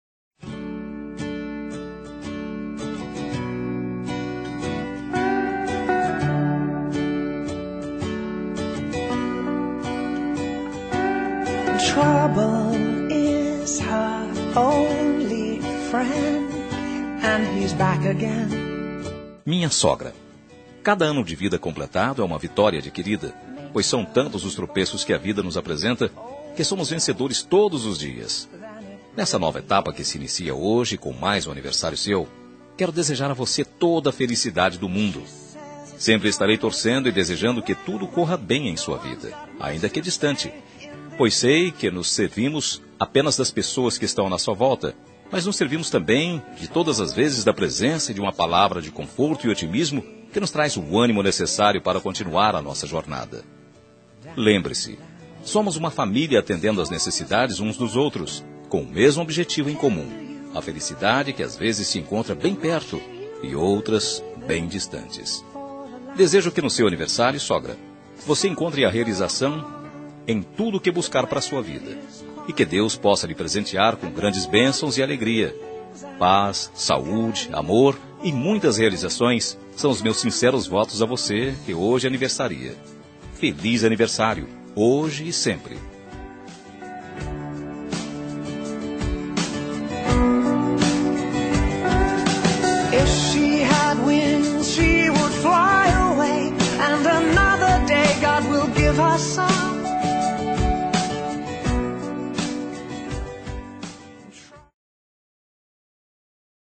Telemensagem Aniversário de Sogra – Voz Masculina – Cód: 1983